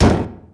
canon_machine_02.mp3